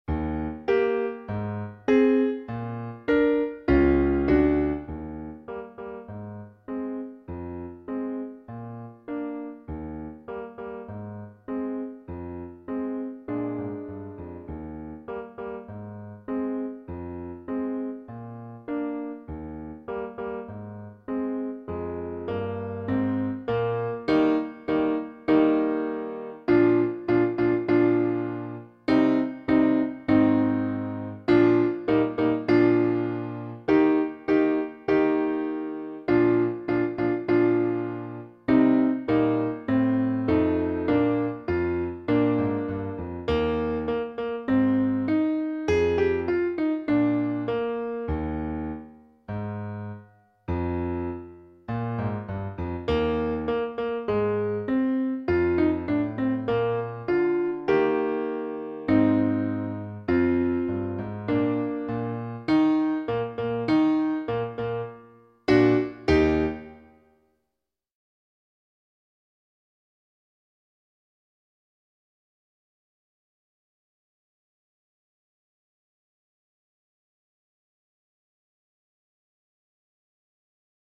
Beginning Band Practice Recordings and Accompaniments:
(Accompaniment Only)